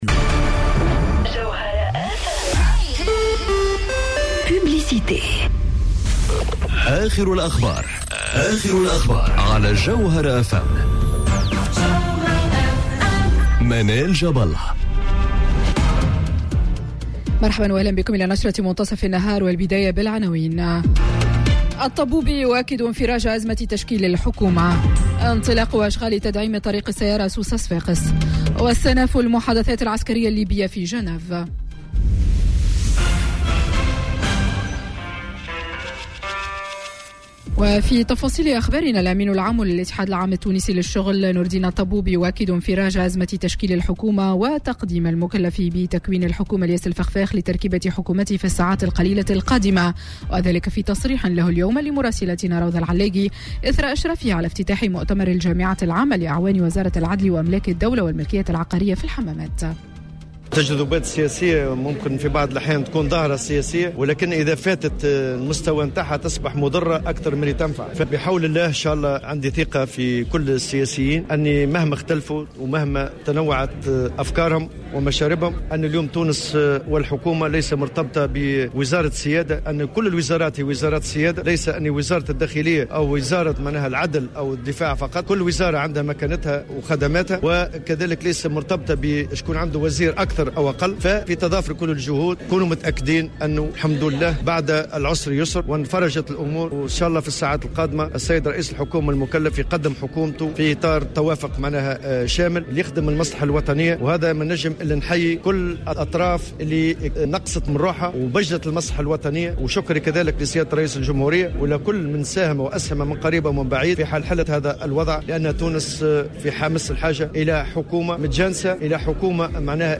نشرة أخبار منتصف النهار ليوم الثلاثاء 18 فيفري 2020